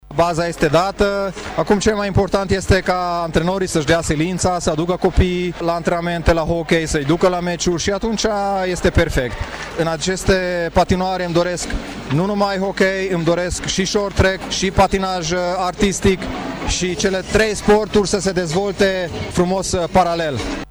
Ministrul Sportului, Novak Eduard: